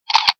camera.wav